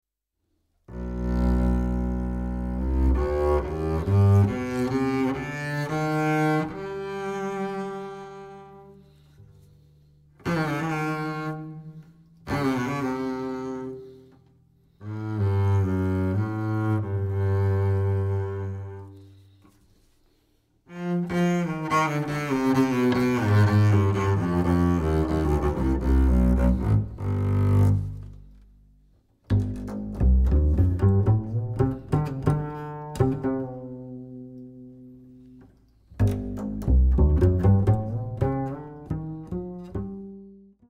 Accordéon / Ténor Lyrique
Trombone
Contrebasse
au Studio Les Tontons Flingueurs (Renaison - France)